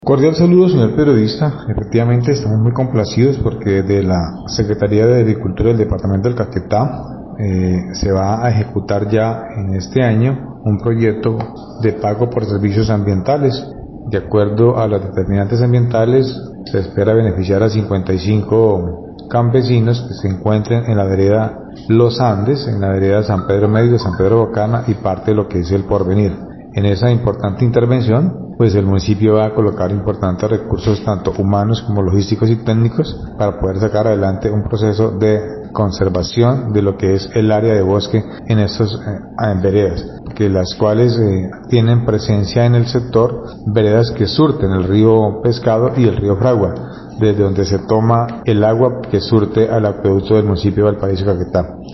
Arbenz Pérez Quintero, alcalde del municipio de Valparaíso, explicó que, los beneficiarios se ubican en las veredas Los Andes, San Pedro Medio, San Pedro Bocana y parte del Porvenir, quienes protegerán a estos dos importantes afluentes hídricos.
ALCALDE_ARBENZ_PEREZ_FORESTALES_-_copia.mp3